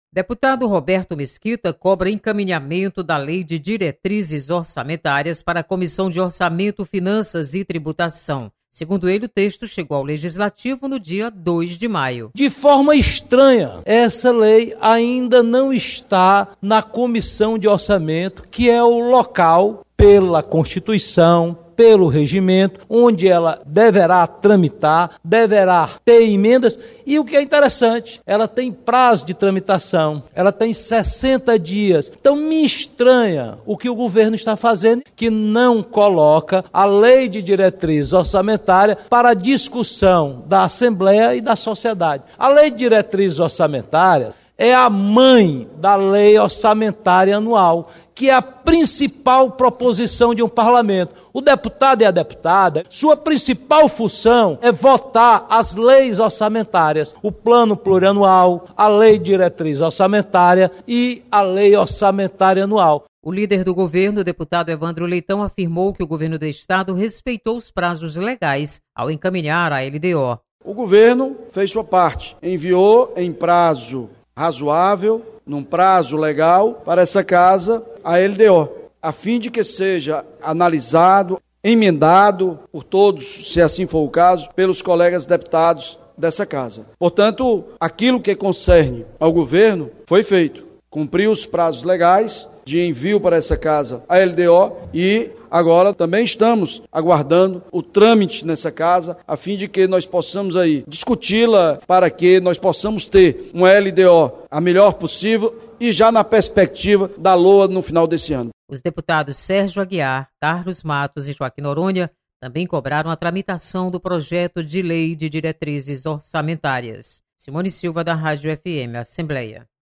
Deputados divergem sobre tramitação da LDO. Repórter